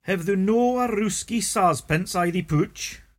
[hev thoo no a roosky sarz-pns ay thee pooch?]